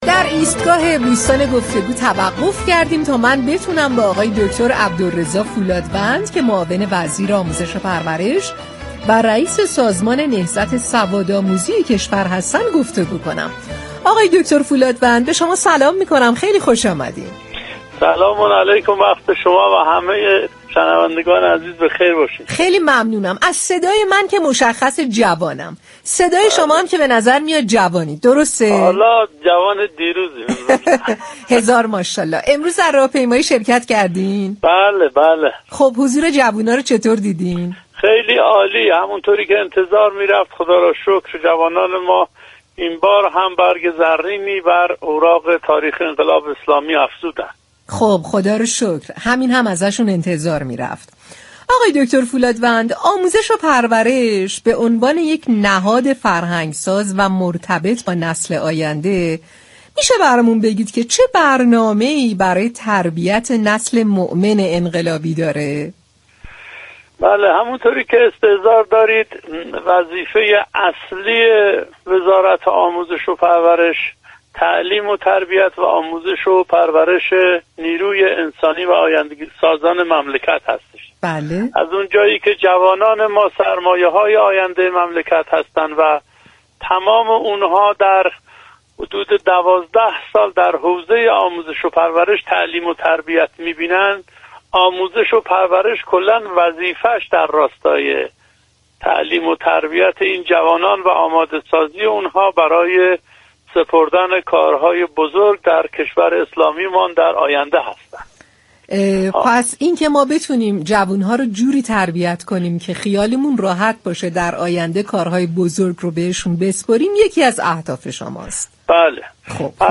به گزارش پایگاه اطلاع رسانی رادیو تهران، عبدالرضا فولادوند معاون وزیر آموزش و پرورش و رئیس سازمان نهضت سوادآموزی در گفت و گو با «شهر زیبا» اظهار داشت: وظیفه اصلی وزارت آموزش و پروش تعلیم و تربیت آینده‌سازان كشور است و آماده كردن آنها برای سپردن كارهای بزرگ به آنها است.